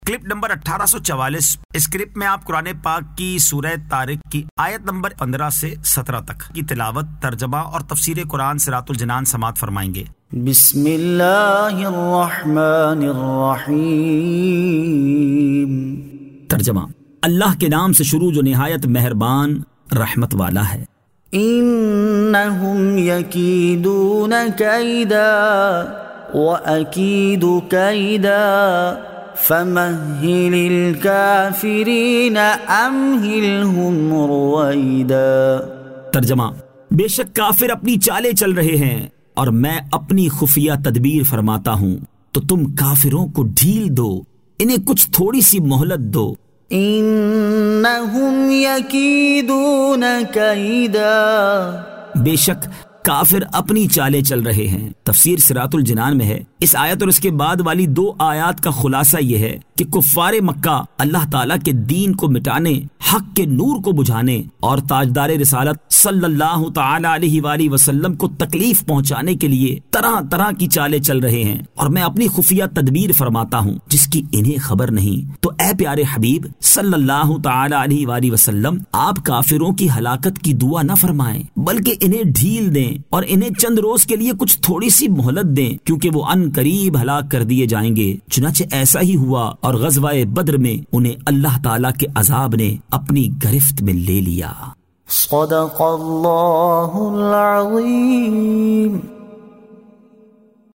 Surah At-Tariq 15 To 17 Tilawat , Tarjama , Tafseer